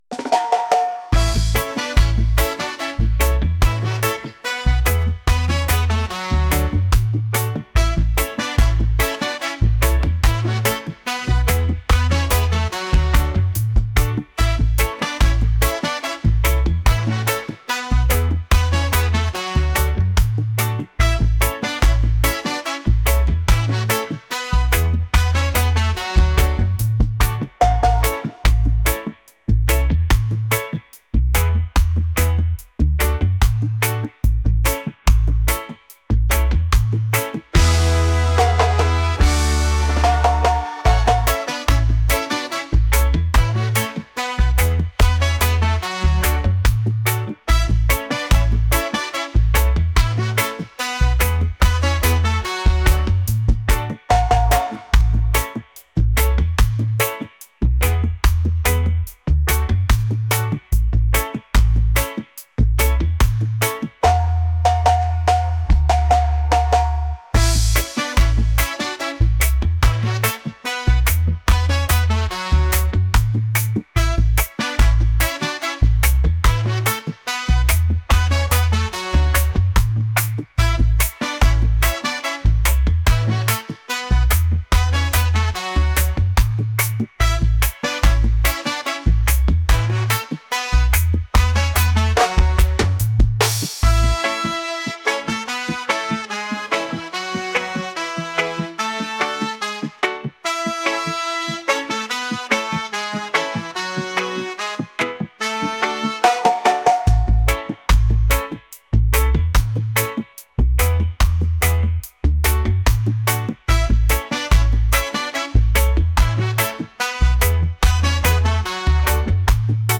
reggae | pop | folk